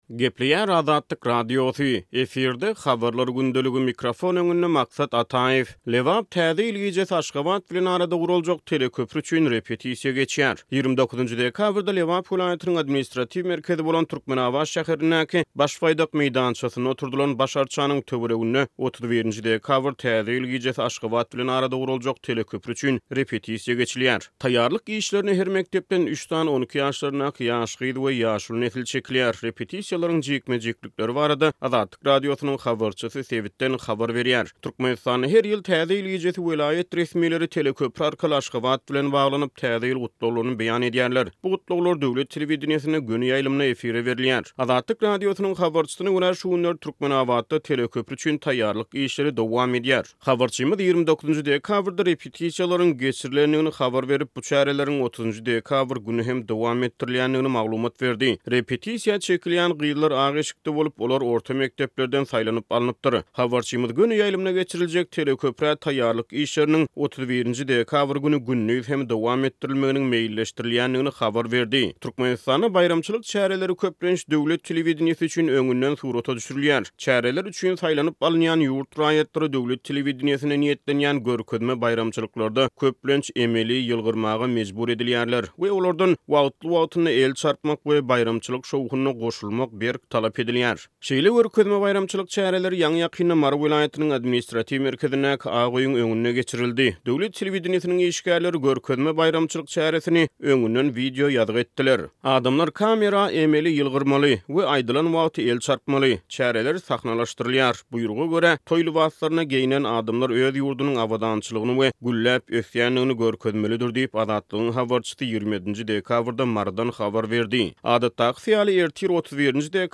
Taýýarlyk işlerine her mekdepden üç sany 12 ýaşlaryndaky ýaş gyz we ýaşuly nesil çekilýär. Repetisiýalaryň jikme-jiklikleri barada Azatlyk Radiosynyň habarçysy sebitden habar berýär.